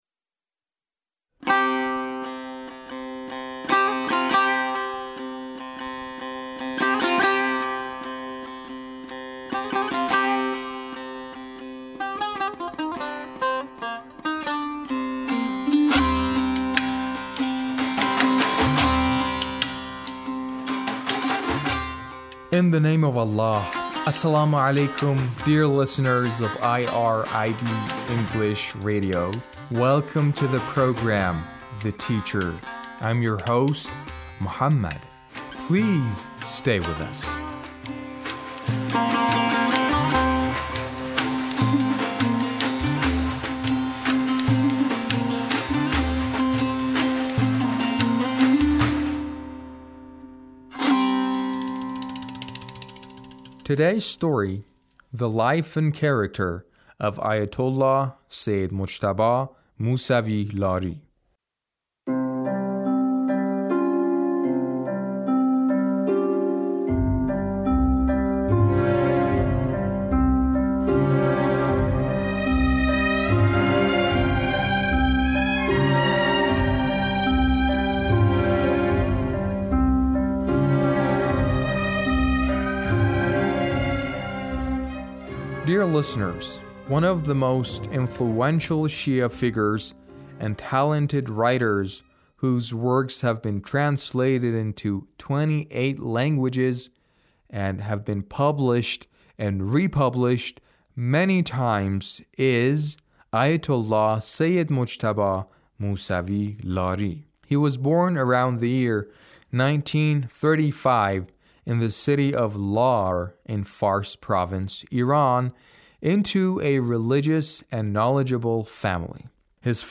A radio documentary on the life of Ayatullah Sayyid Mujtaba Musavi Lari - 1